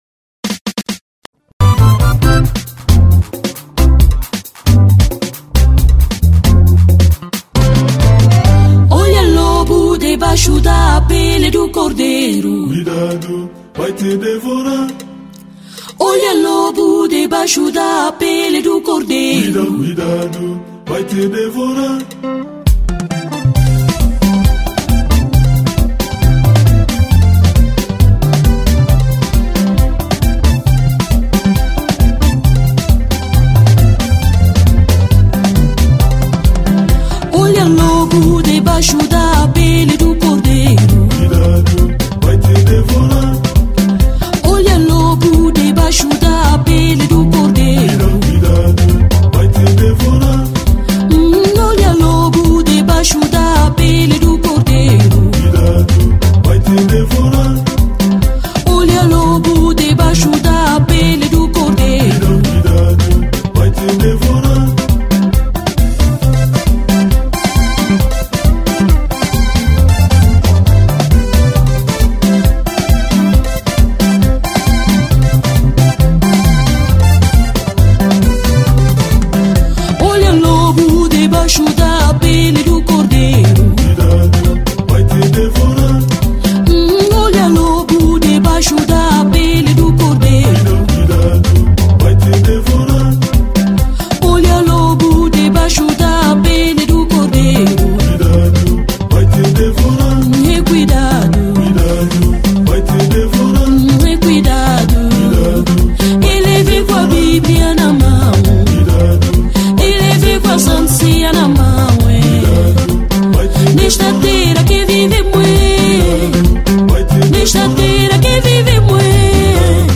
Gospel 2012